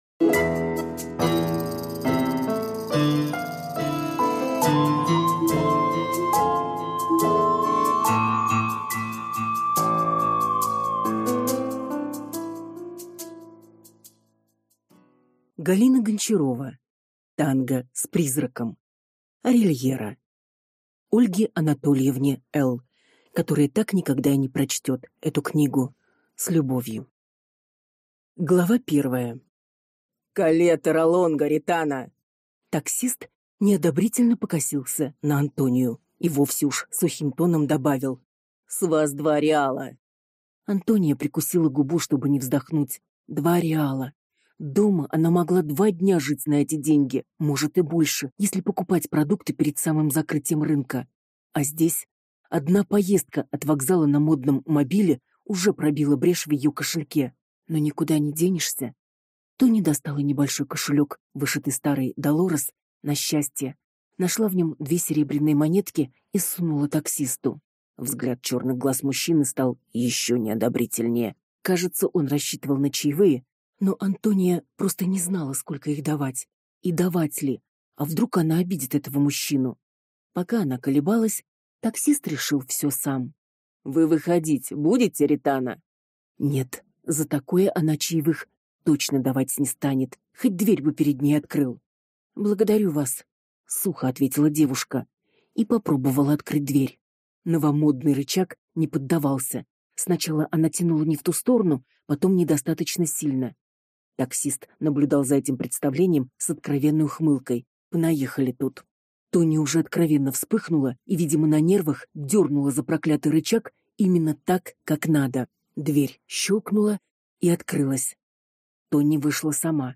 Аудиокнига Танго с призраком (Сборник) | Библиотека аудиокниг